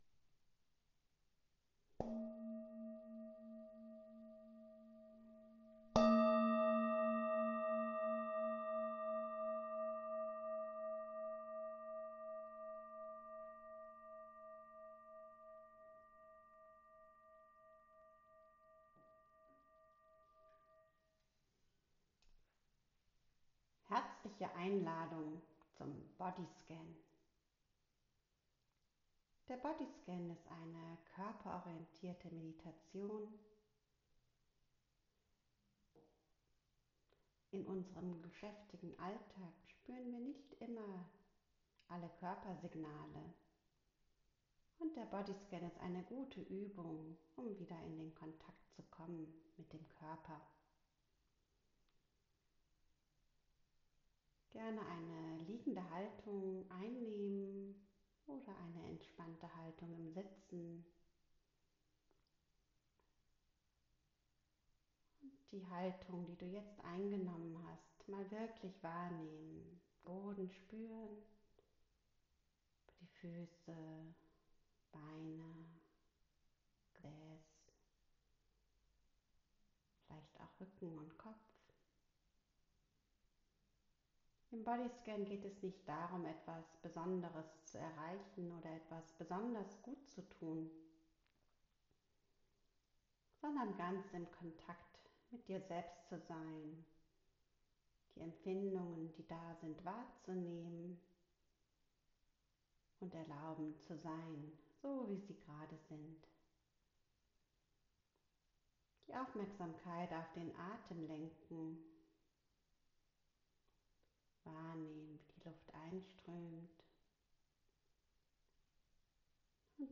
2. Bodyscan
Bodyscan_9_min_final.mp3